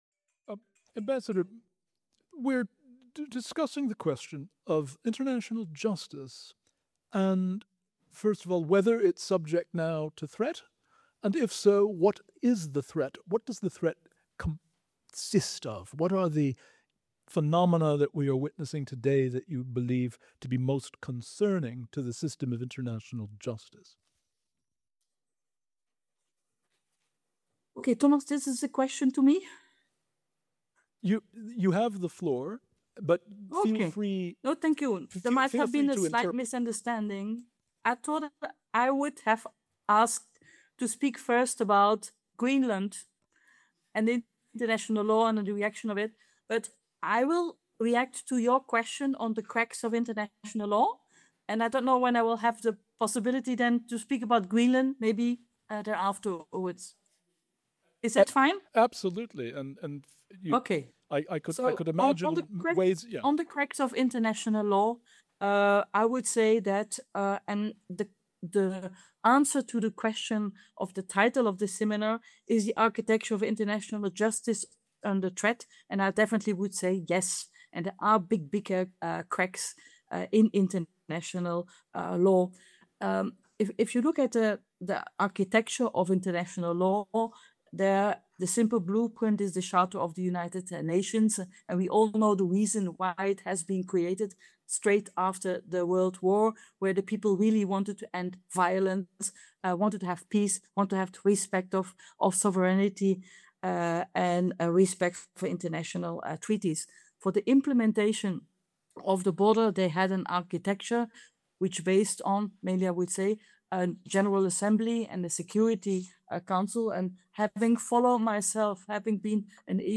The Sixth Annual Wolfson Sir David Williams Law Society Event was held on 7 March 2026.
This Wolfson College Cambridge event featured two insightful panel discussions with distinguished law professionals; an afternoon tea and a formal dinner to conclude the day.